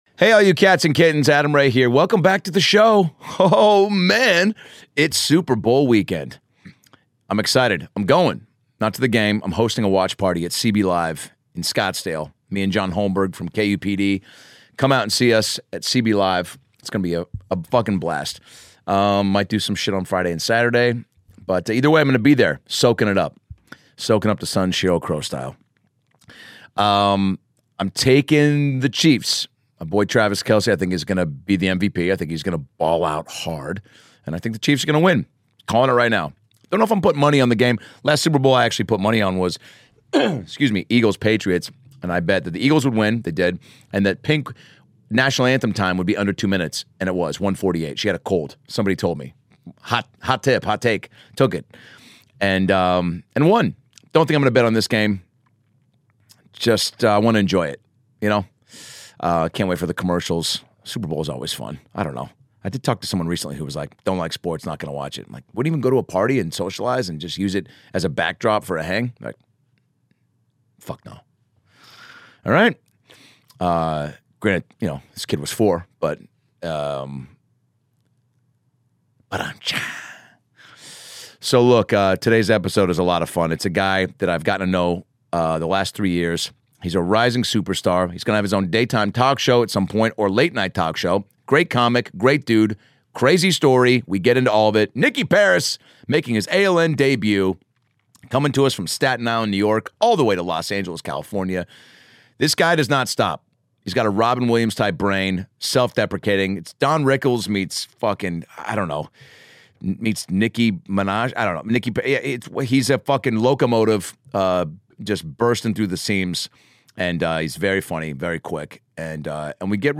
Live From The Comedy Store